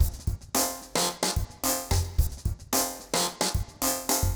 RemixedDrums_110BPM_41.wav